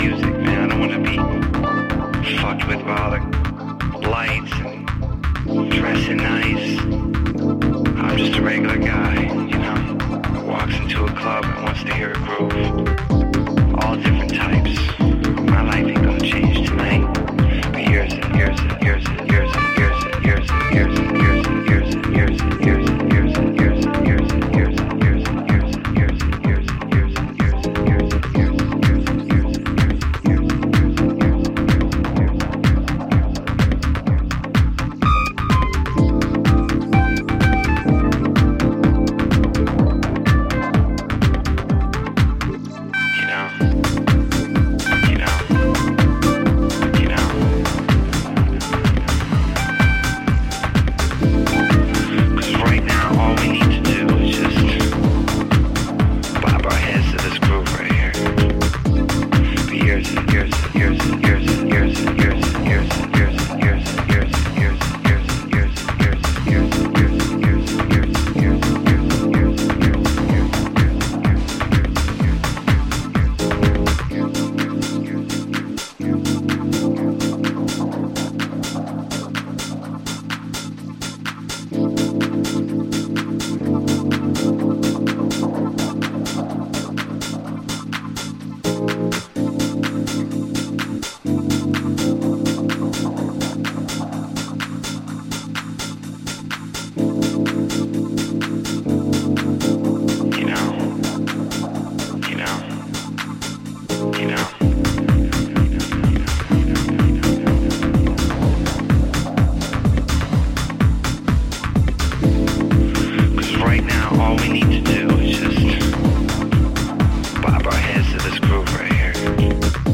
ハウス、ジャズ、ブレイクビーツがブレンドされたおすすめ盤です！